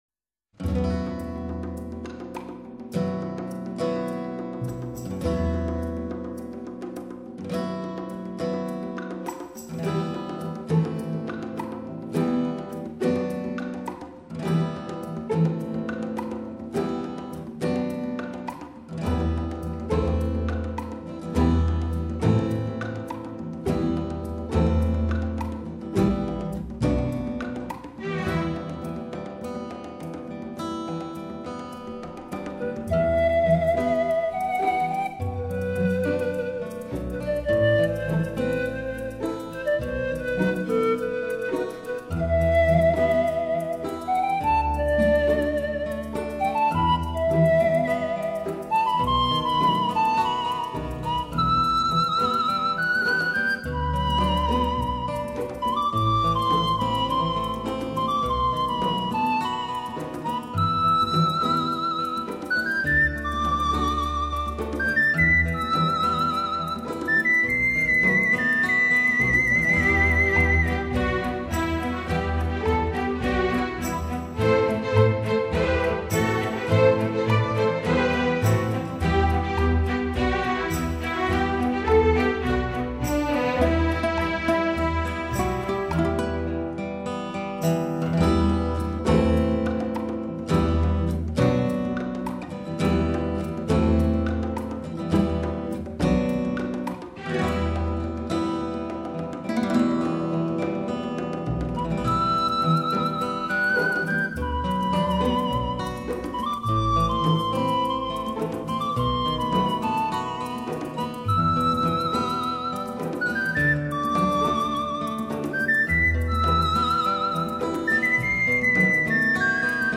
音乐类型：New  age
首张以传统笛子与排箫演绎中、德、日、法、希腊、挪威等全球顶尖作曲家畅销曲目。